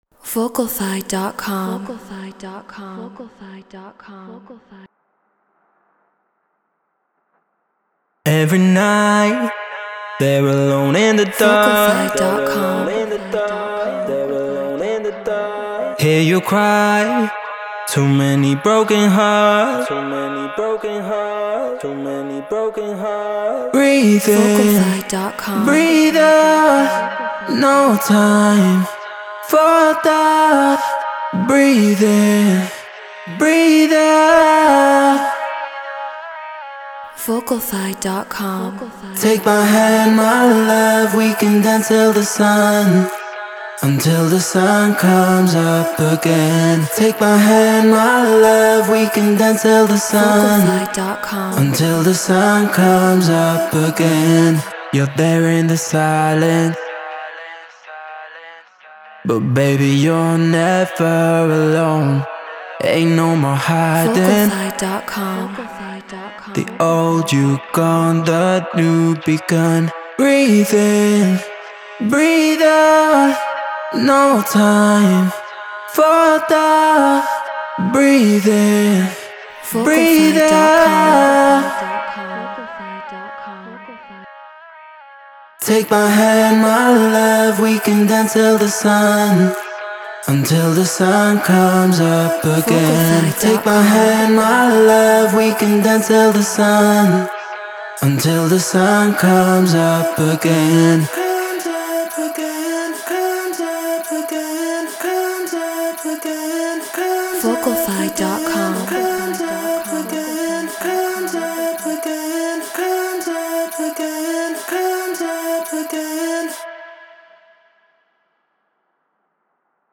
Future Garage 124 BPM C#min
Human-Made